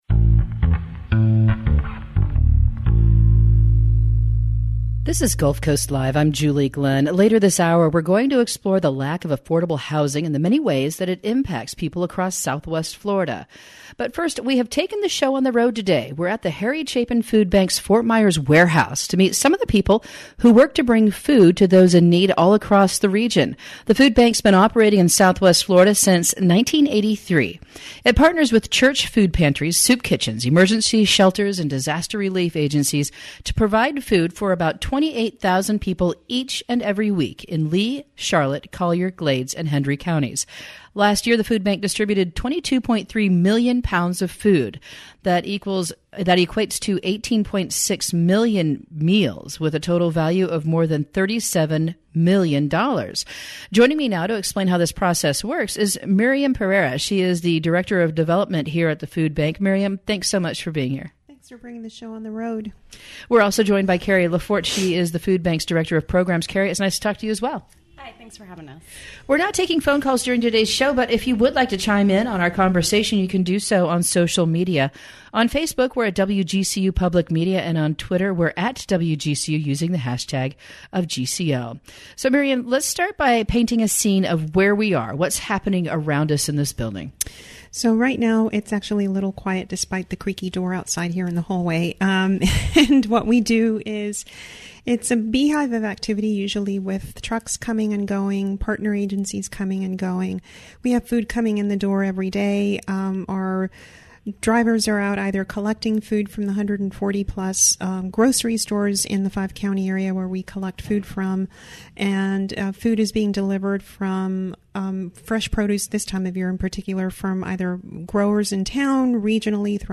Live on Location at the Harry Chapin Food Bank
We're taking the show on the road to the Harry Chapin Food Bank’s Fort Myers warehouse to meet some of the people who work to bring food to those in...